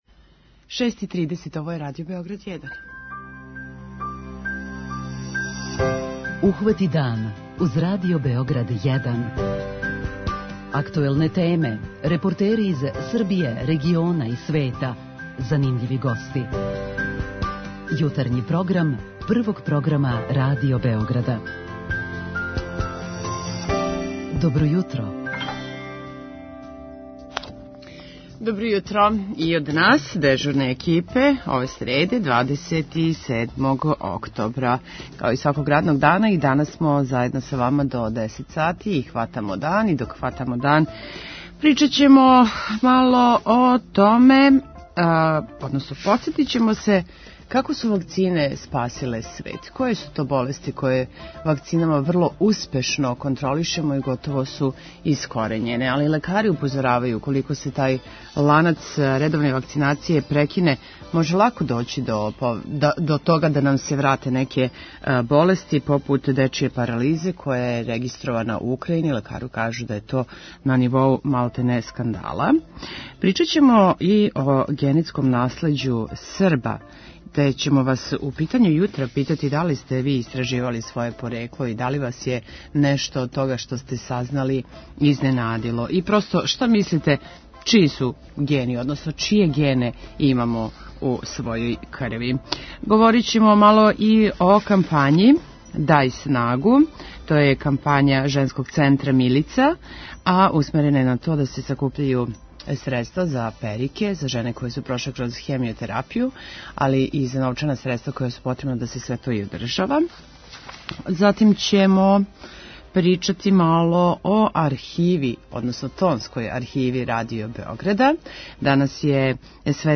Светска здравствена организација је овај тренд уврстила у једну од 10 претњи светском здрављу. У јутарњем програму стручњаци из различитих области објасниће нам како су вакцине спасиле човечанство. преузми : 37.79 MB Ухвати дан Autor: Група аутора Јутарњи програм Радио Београда 1!